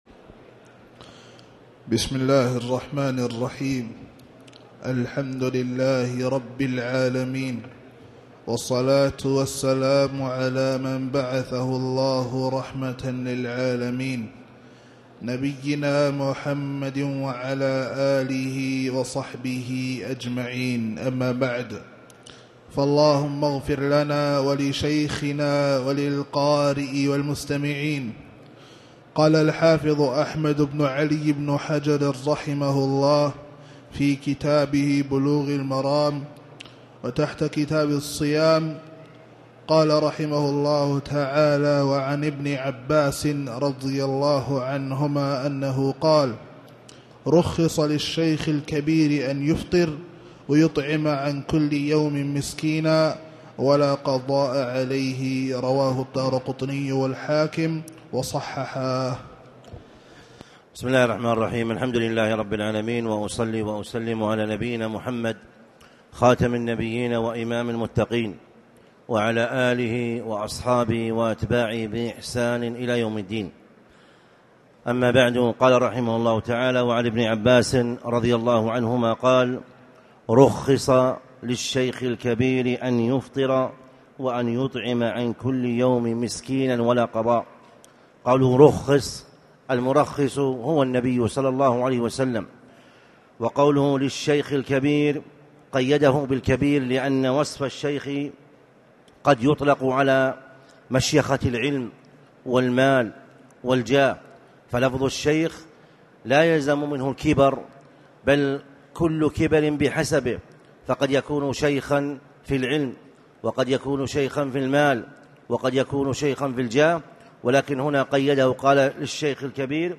تاريخ النشر ٢٢ شعبان ١٤٣٨ هـ المكان: المسجد الحرام الشيخ